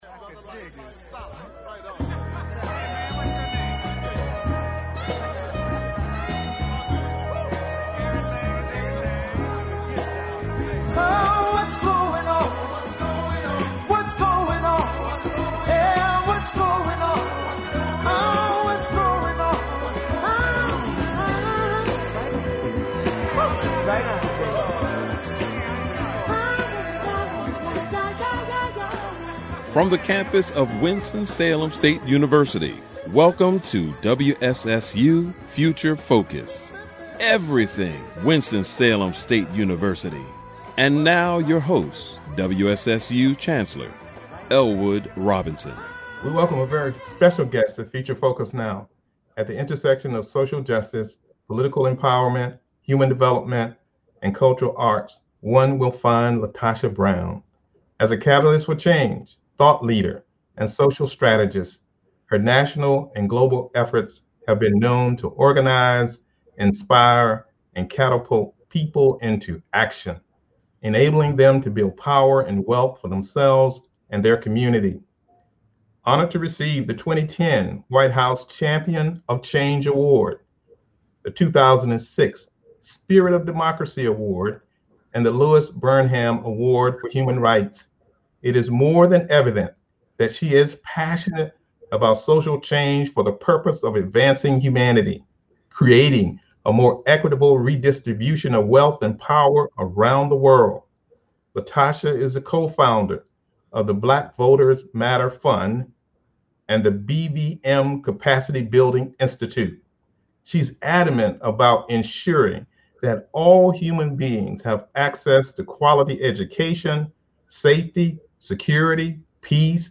one-hour public affairs talk show